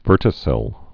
(vûrtĭ-sĭl)